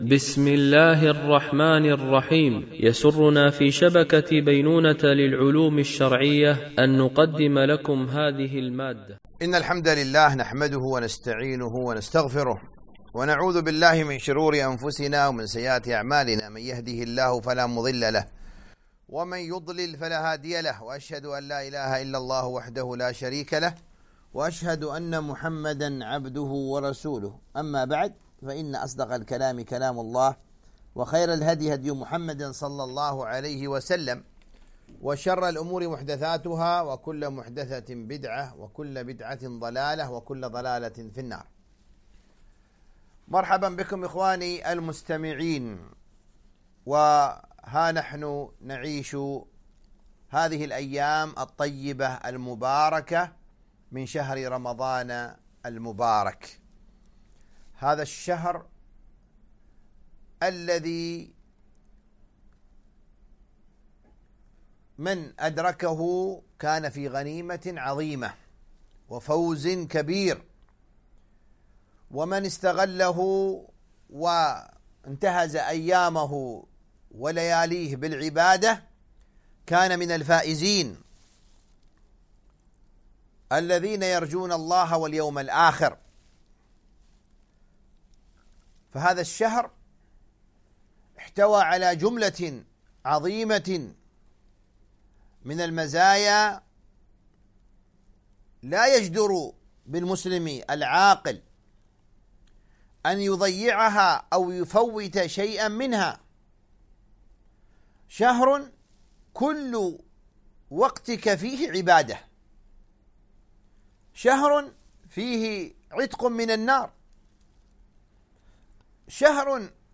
تفسير جزء قد سمع ـ الدرس 01 ( سورة المجادلة - الجزء الأول )